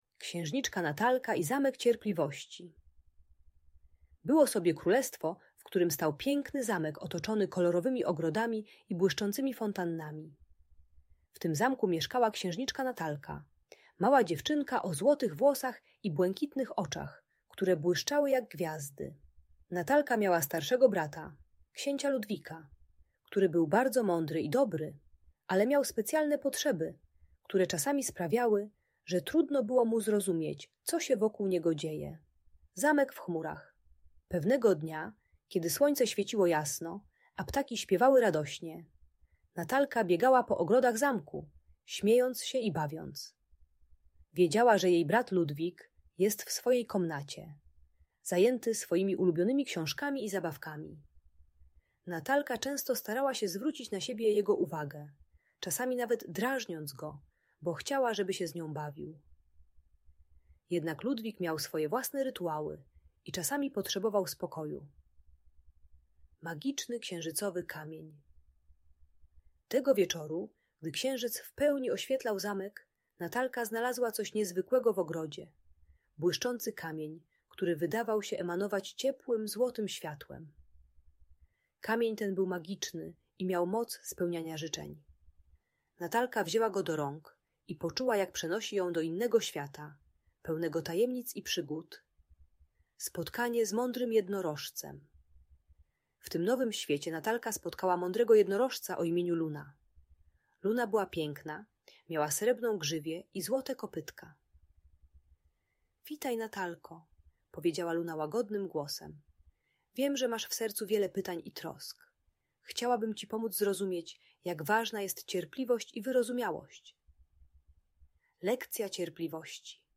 Księżniczka Natalka - Rodzeństwo | Audiobajka